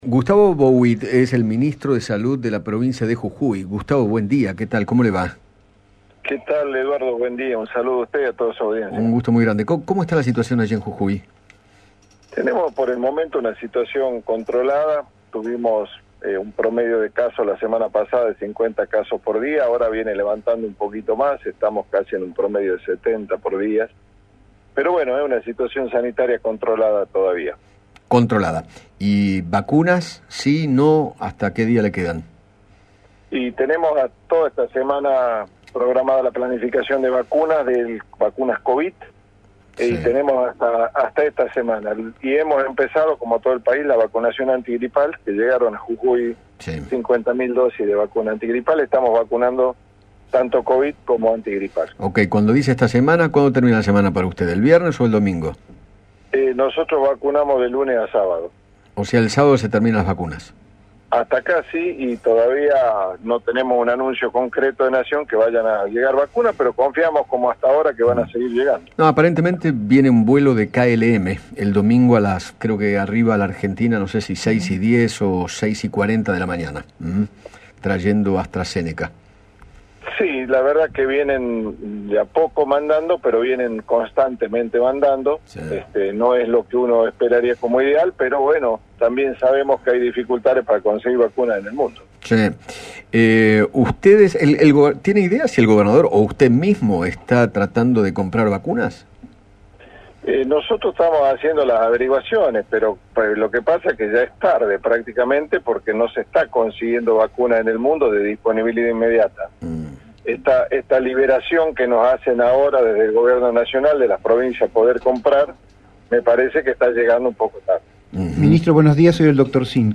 Gustavo Bouhid, ministro de Salud de Jujuy, dialogó con Eduardo Feinmann acerca de la campaña de vacunación y se refirió a la situación sanitaria que atraviesan.